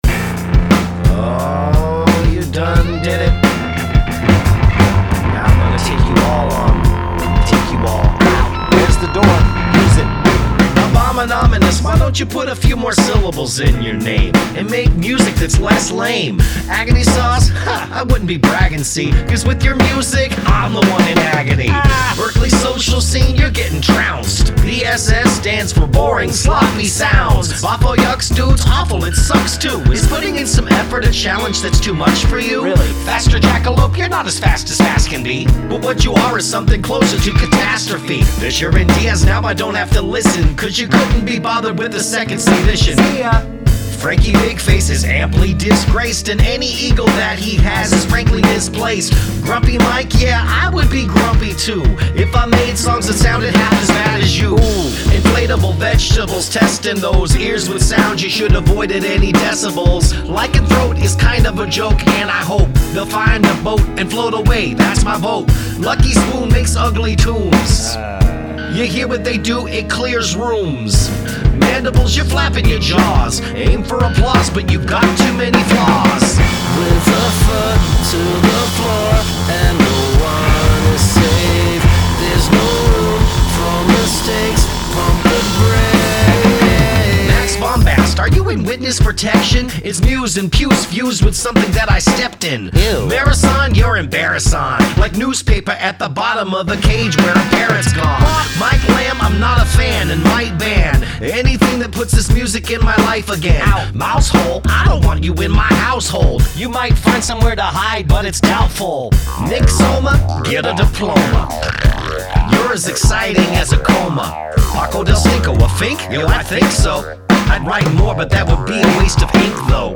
Diss Track